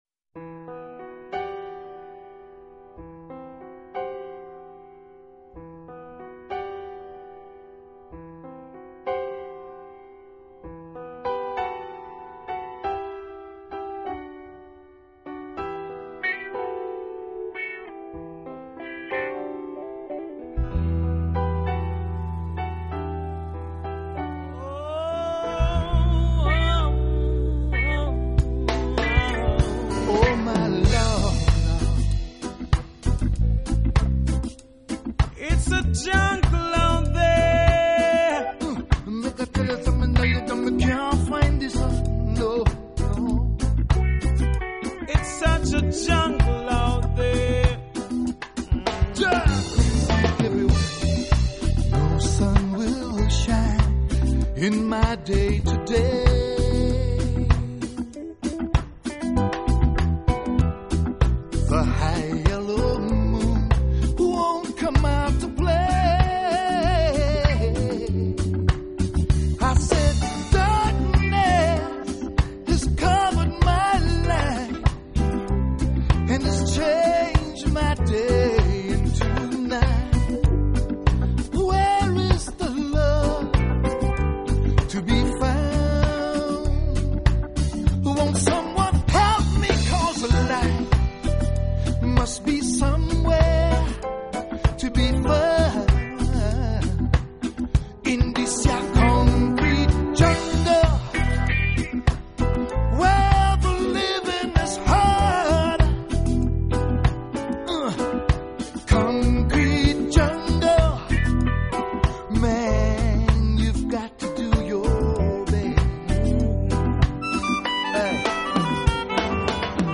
音樂類別 ： 爵士樂 ． 爵士鋼琴
專輯特色 ： 爵士，豔陽，牙買加。節奏、歌聲隨樂音隨興飄揚!
單純的觸鍵與輕巧的語法，讓音樂的本色自己說話。